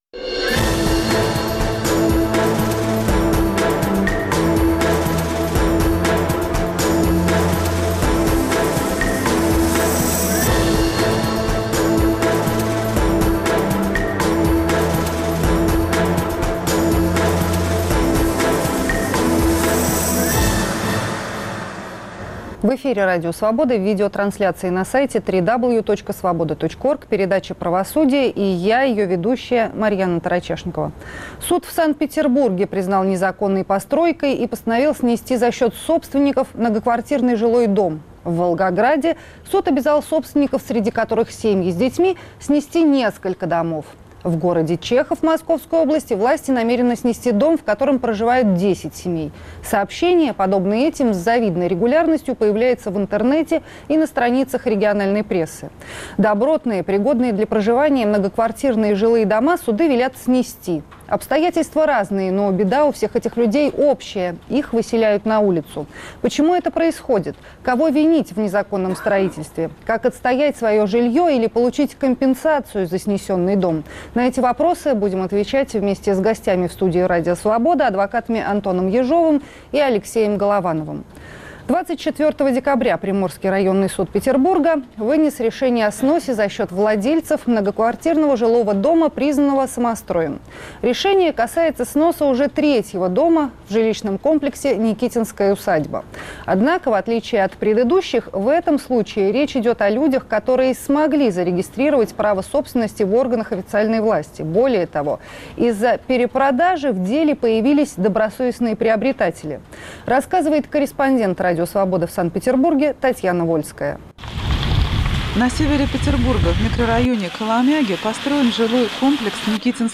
Как отстоять свое жилье или получить компенсацию за снесенный дом? В студии адвокаты